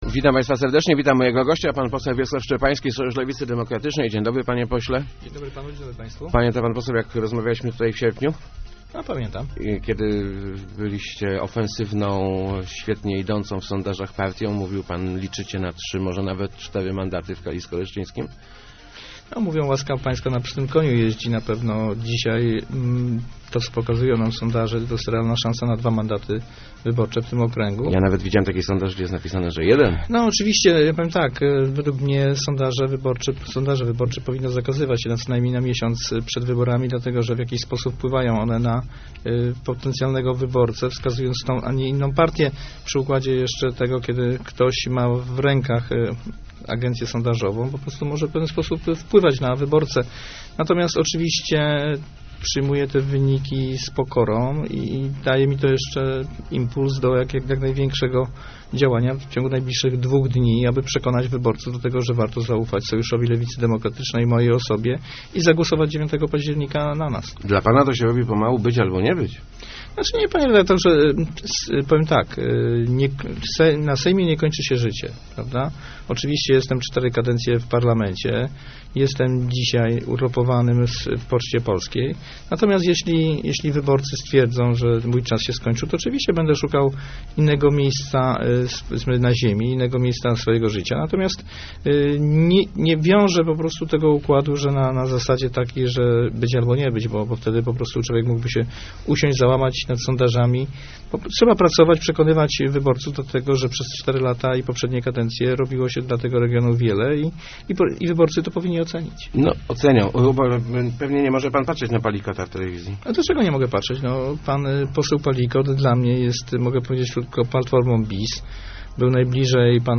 Kampanii wyborczej nie wygrywa się nie górze, tylko w okręgach - mówił w Rozmowach Elki poseł SLD Wiesław Szczepański. Jego zdaniem Sojusz broni się czterema latami pracy w opozycji, jednak poseł przyznaje, że kampania wyborcza nie była zbyt wyrazista.